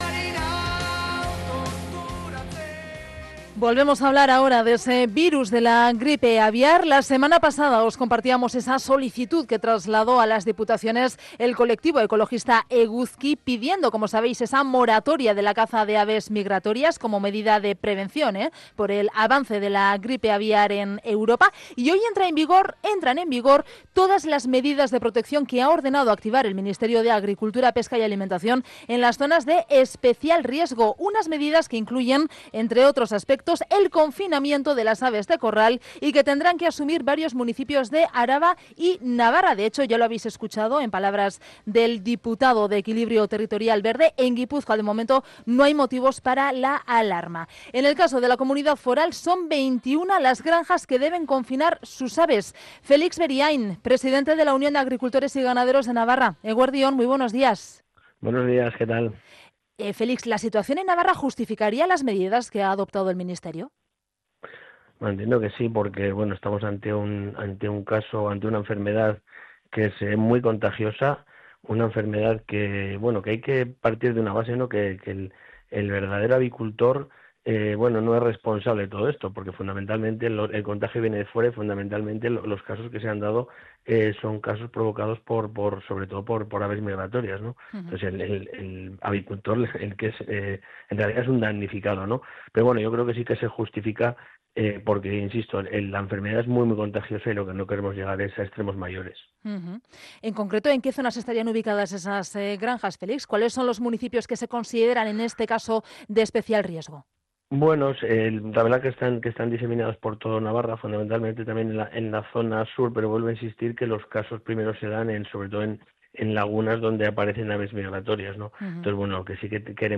Onda Vasca: entrevista